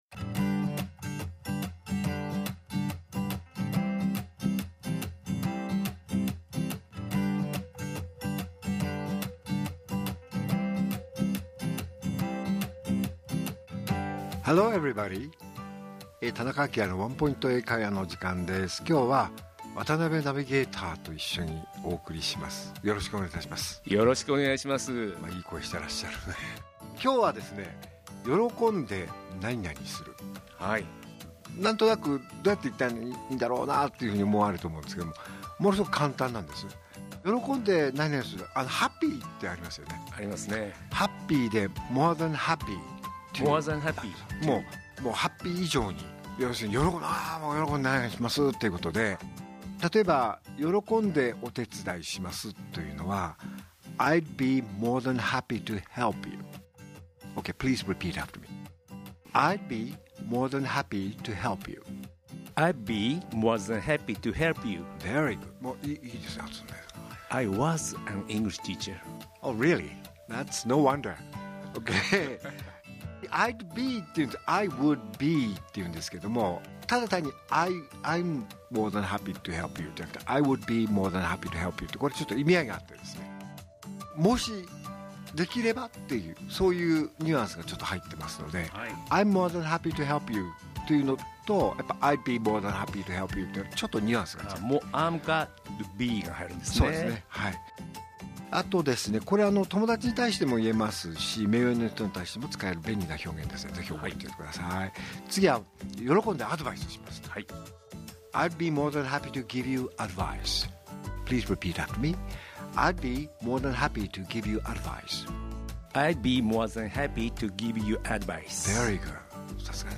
R6.12 AKILA市長のワンポイント英会話